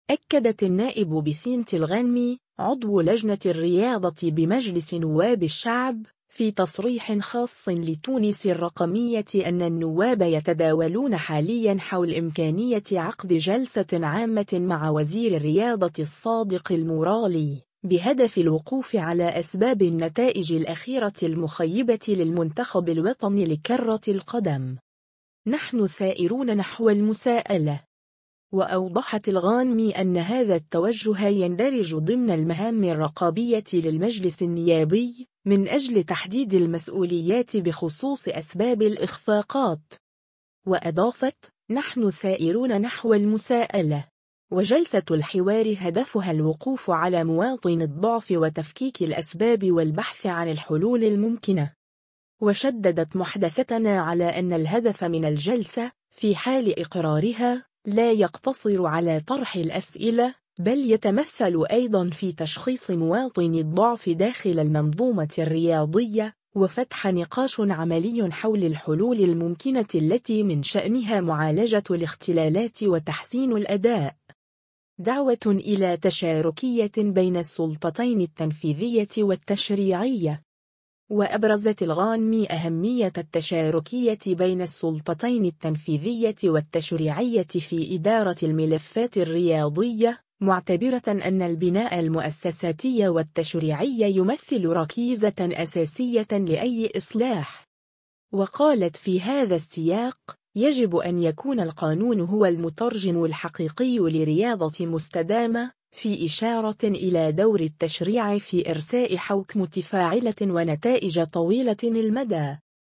أكدت النائب بثينة الغانمي، عضو لجنة الرياضة بمجلس نواب الشعب، في تصريح خاص لـ”تونس الرقمية” أن النواب يتداولون حاليًا حول إمكانية عقد جلسة عامة مع وزير الرياضة الصادق المورالي، بهدف الوقوف على أسباب النتائج الأخيرة المخيبة للمنتخب الوطني لكرة القدم.